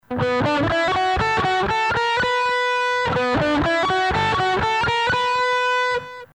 Пример звука Zoom 707
Записан в линию  (SB Live5.1)
Записано на гитаре Fender Squier